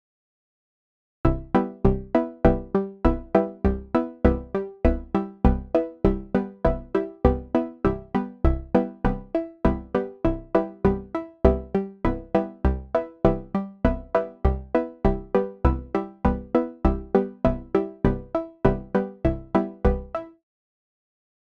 Euklidische Sequenz wie in Abbildung